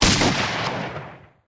Shotgun_SingleShot_2.ogg